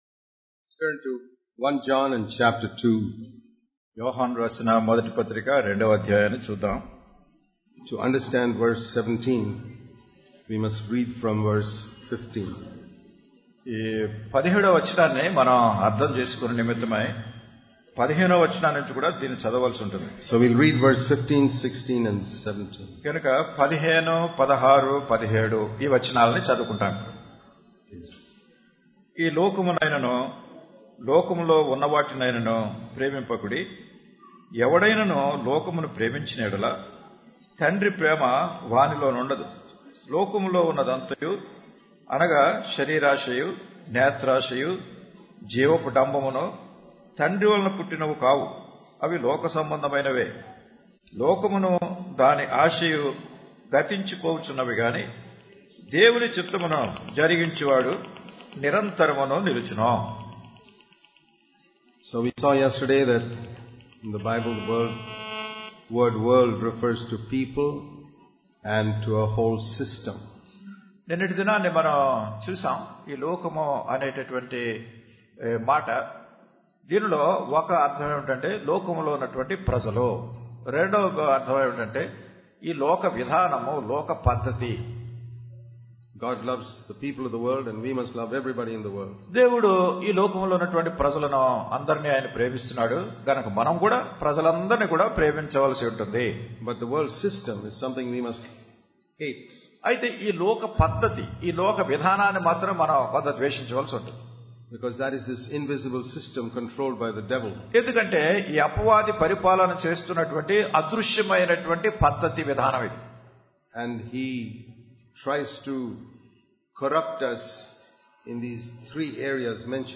at Kakinada Conference 2015 (with Telugu translation) Click here to View All Sermons